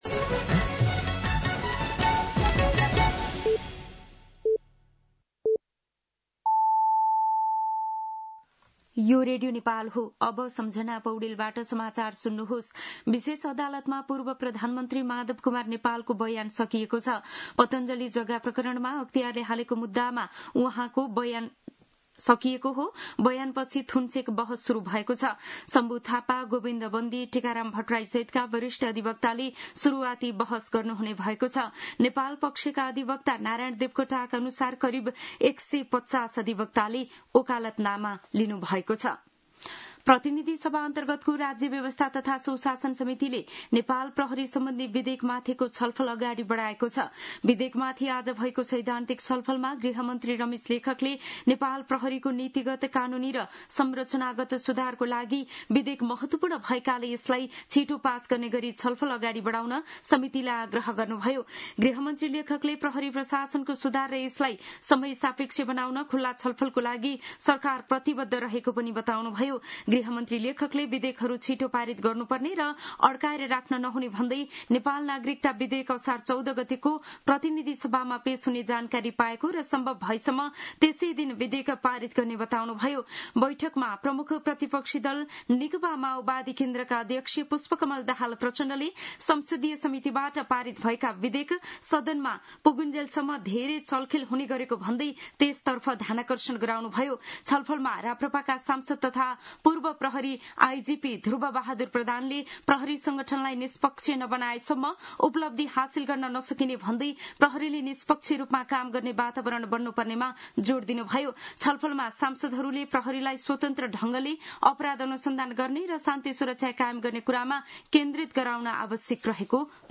दिउँसो ४ बजेको नेपाली समाचार : ११ असार , २०८२